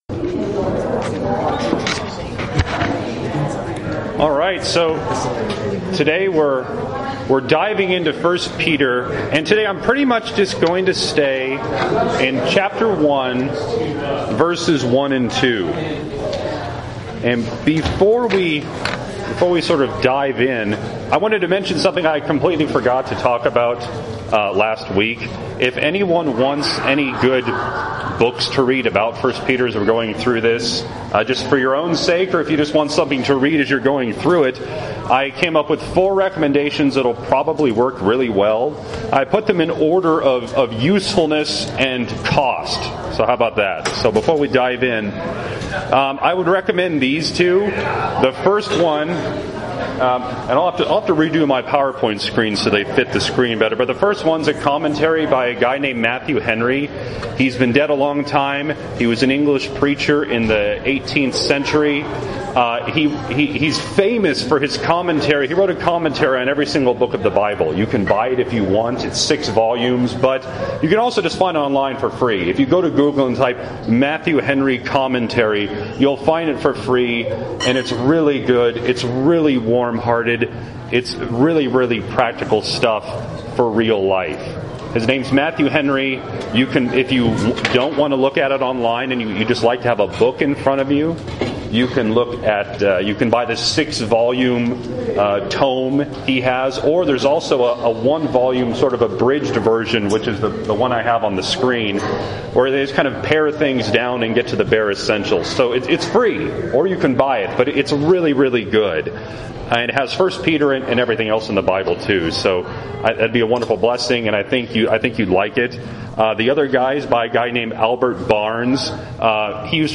Here are the notes for this week’s Sunday School.